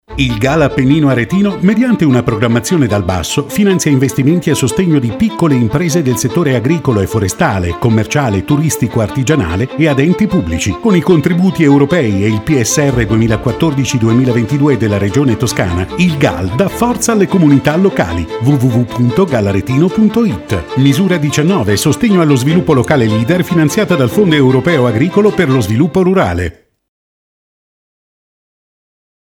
Radio Valtiberina spot n. 4
Spot-n.-4-Radio-Valtiberina.mp3